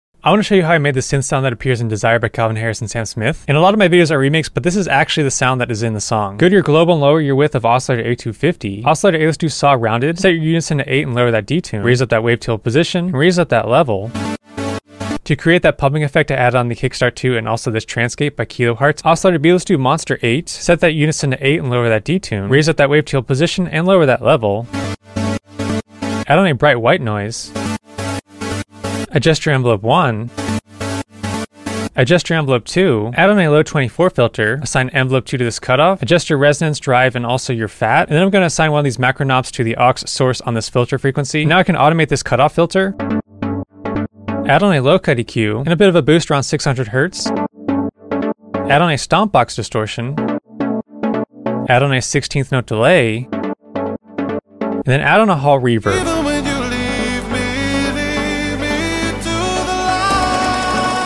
synth
sound design